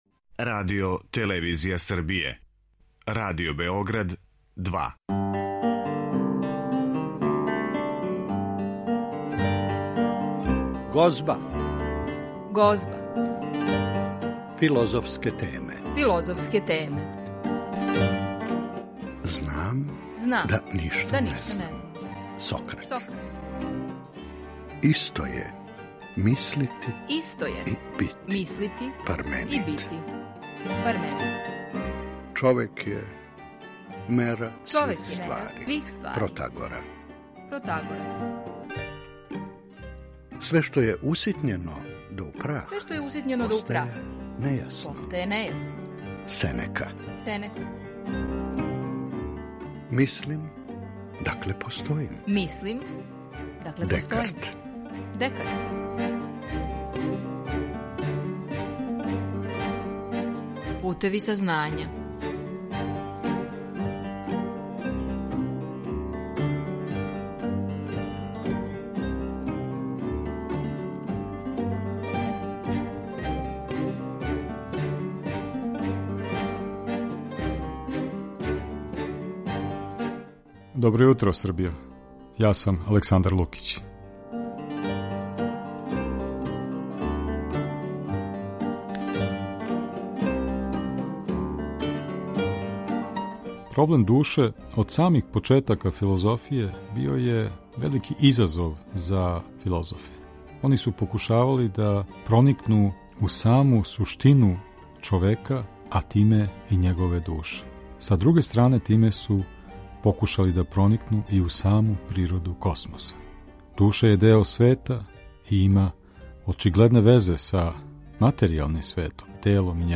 Разговор води